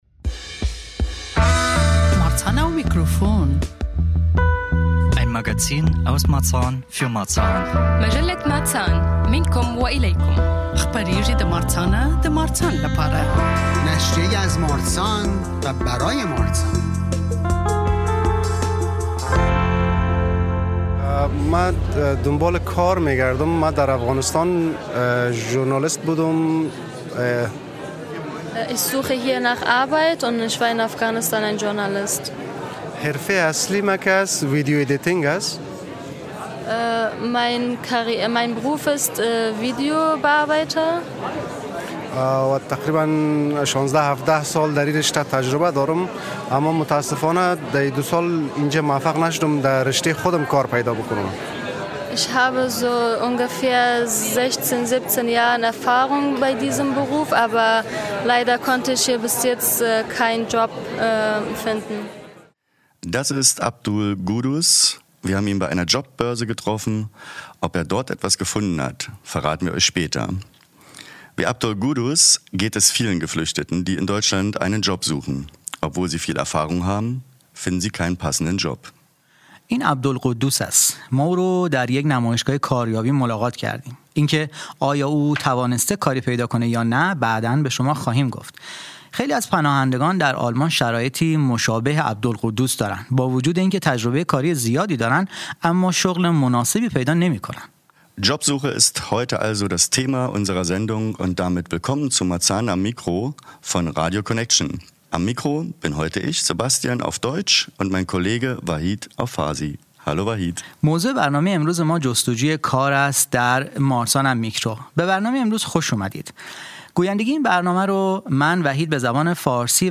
Wir waren am 14. März 2025 vor Ort im Cabuwazi in Marzahn. Neben den Veranstaltenden haben wir mit Arbeitgeber*innen und Arbeitsuchenden gesprochen. Hört rein und erfahrt mehr über Möglichkeiten und Hindernisse bei der Jobsuche, was Blitzjobs sind oder wie das Bezirksamt mit gutem Beispiel vorangehen möchte.